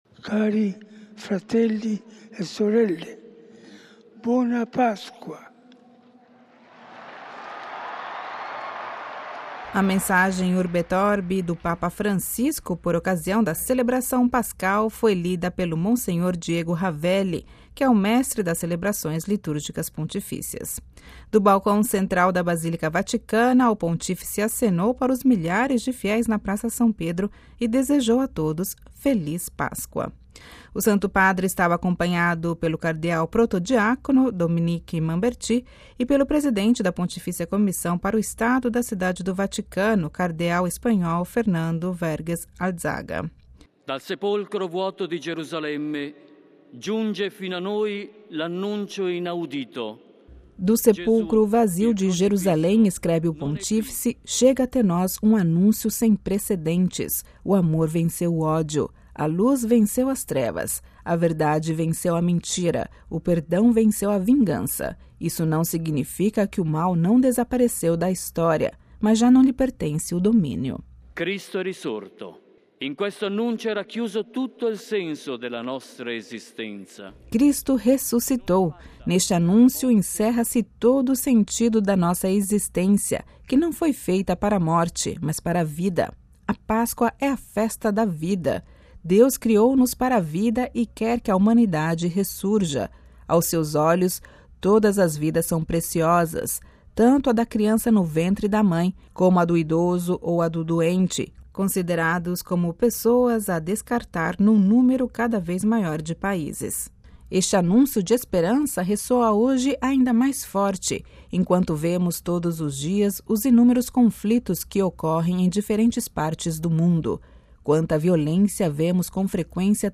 A mensagem "Urbi et orbi" (para a cidade de Roma e para o mundo) do Papa Francisco por ocasião da celebração pascal foi lida por Mons. Diego Ravelli, mestre das Celebrações Litúrgicas Pontifícias. Do balcão central da Basílica Vaticana, o Pontífice acenou para os milhares de fiéis na Praça São Pedro e desejou a todos "Feliz Páscoa".